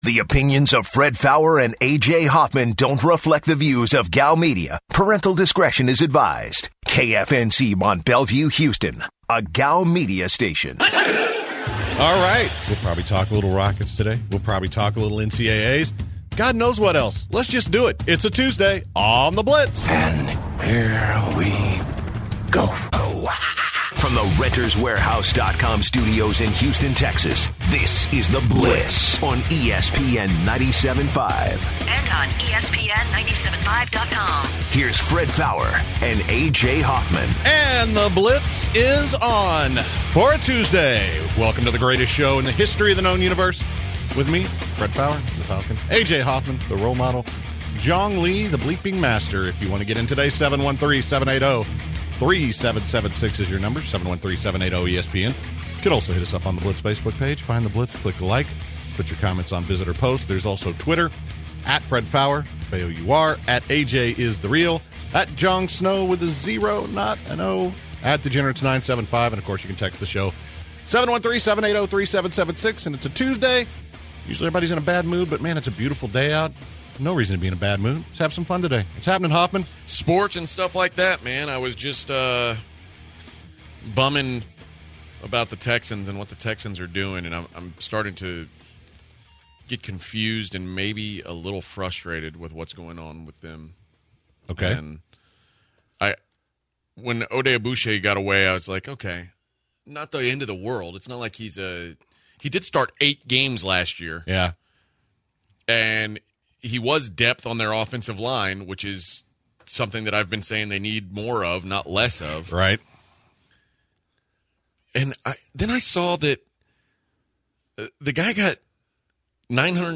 In hour one, the guys react to the Texans not doing anything yet in free agency. Also, the guys took listeners phone calls on the Texans and the NCAA Tournament.